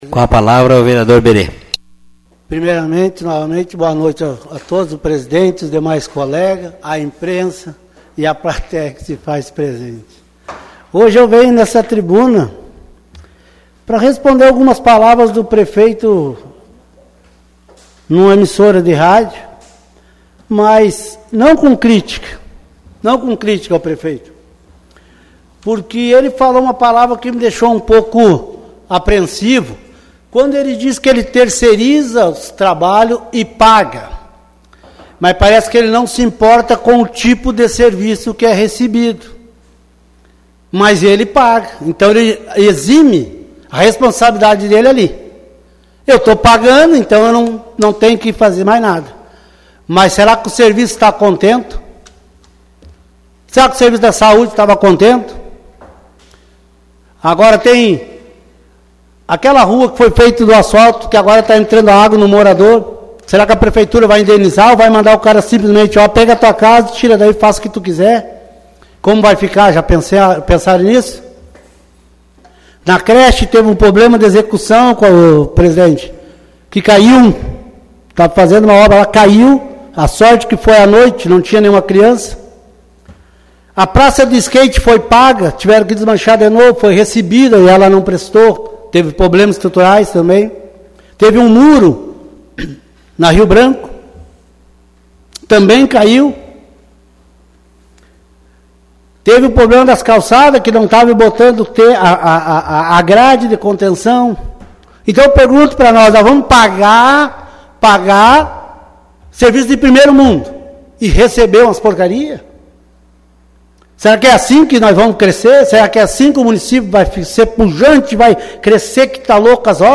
Os vereadores de Sobradinho estiveram reunidos nesta segunda-feira (10), durante a 9ª sessão ordinária.
Apenas o vereador Berê Nunes (PP), fez uso da tribuna. Confira o pronunciamento: